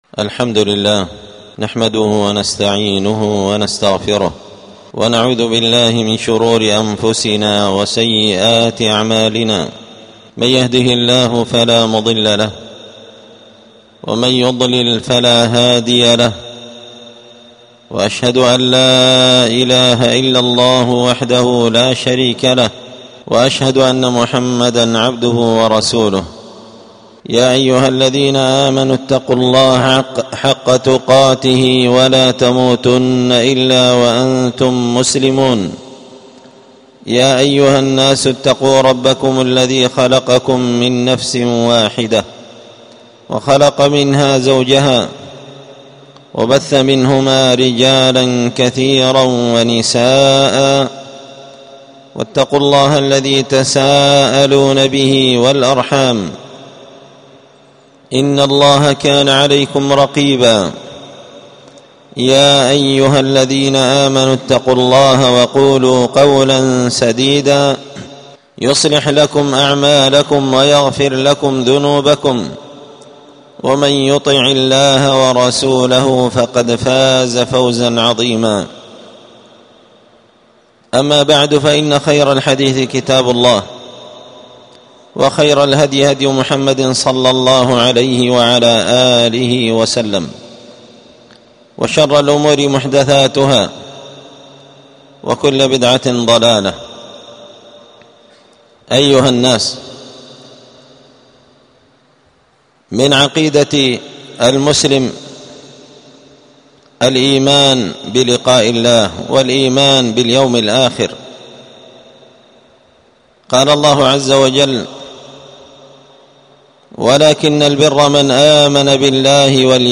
ألقيت هذه الخطبة بدار الحديث السلفية بمسجد الفرقان